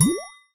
light_pulb_01.ogg